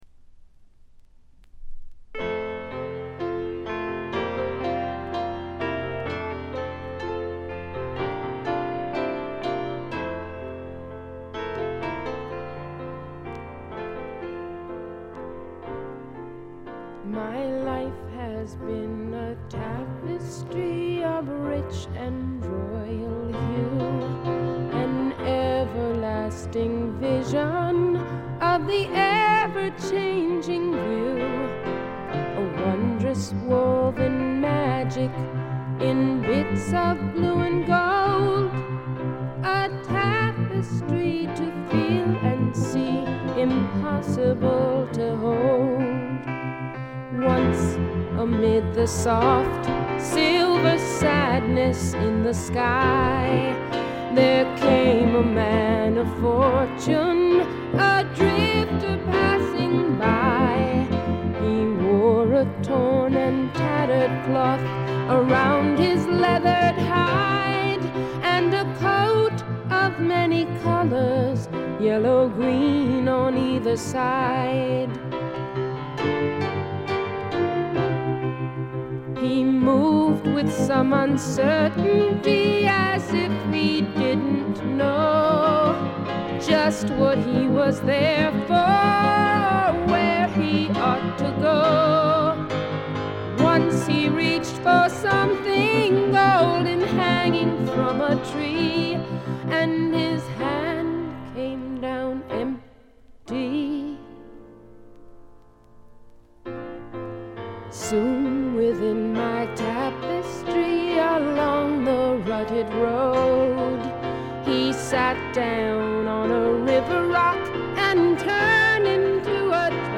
他は微細なチリプチ程度。
女性シンガーソングライター基本中の基本。
試聴曲は現品からの取り込み音源です。